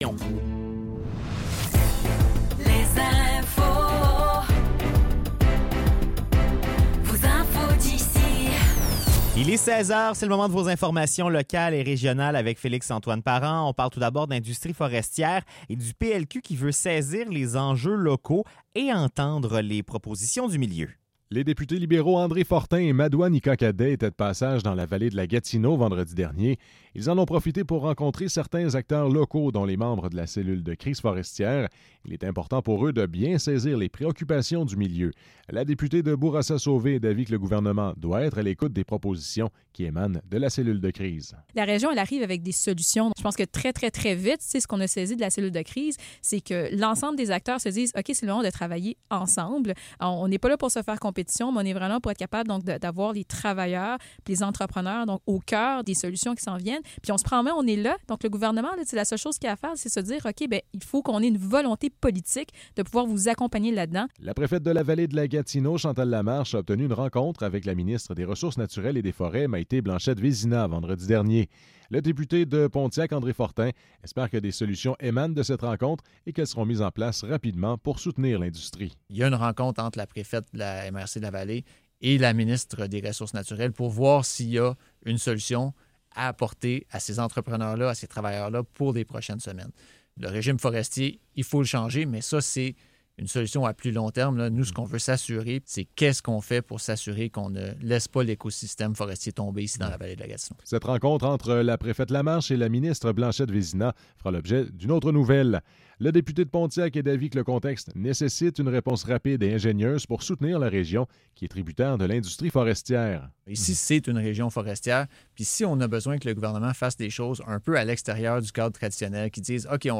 Nouvelles locales - 19 novembre 2024 - 16 h